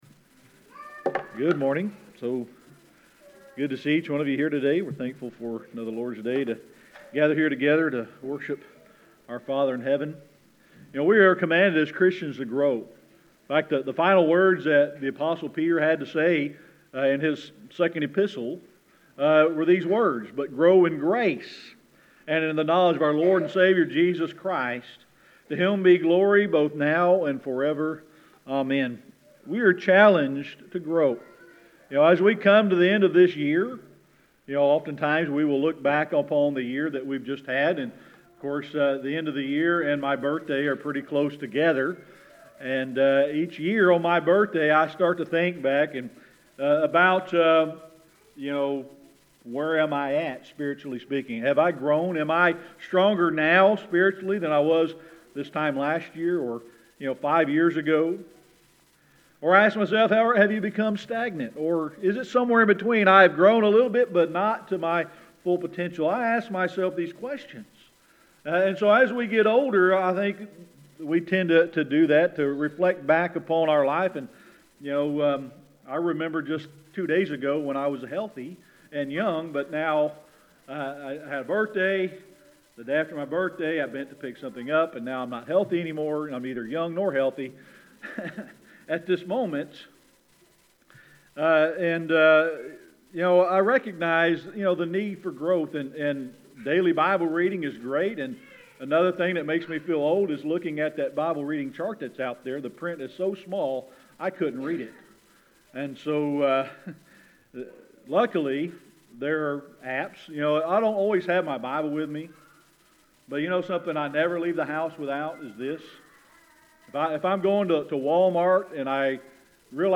2 Peter 3:18 Service Type: Sunday Morning Worship So good to see each one of you here today.